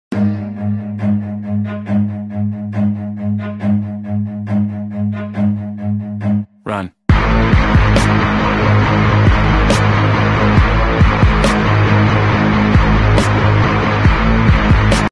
cat running🧡ྀི💛ྀི❤ྀི🧡ྀི💛ྀི❤ྀི